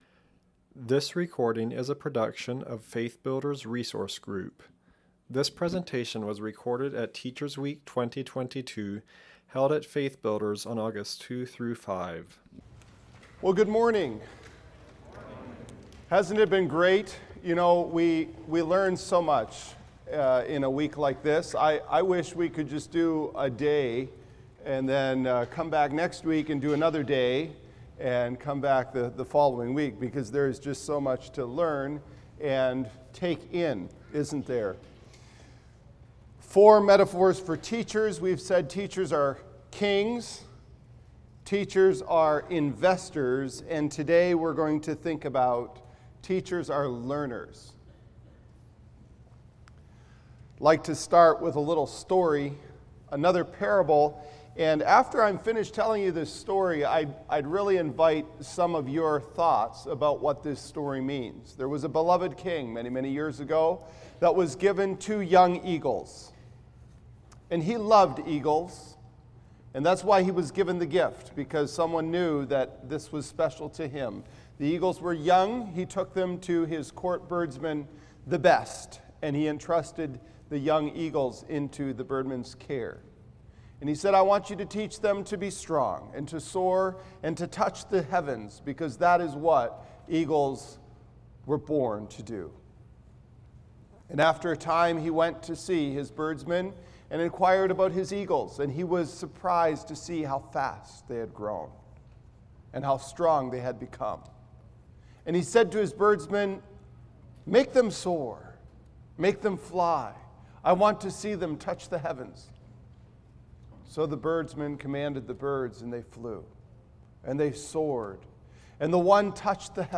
Teachers Week 2022